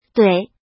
怎么读
duǐ